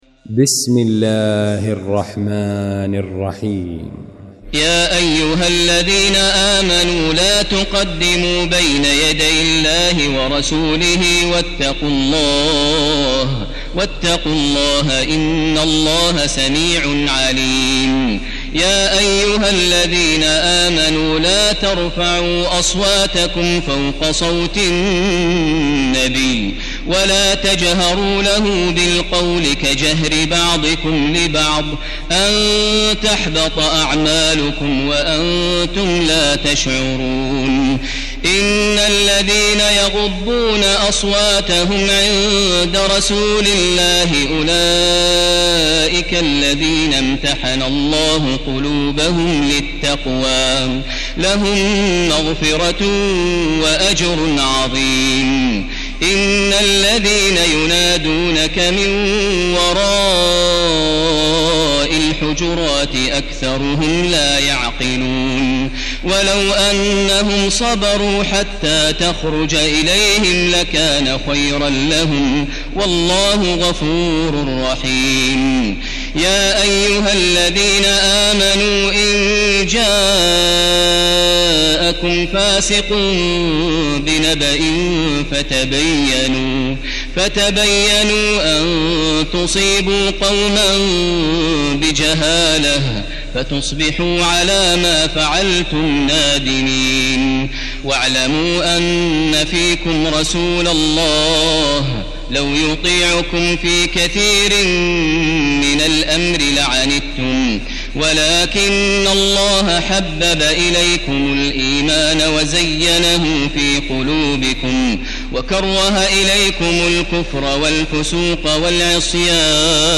المكان: المسجد الحرام الشيخ: فضيلة الشيخ ماهر المعيقلي فضيلة الشيخ ماهر المعيقلي الحجرات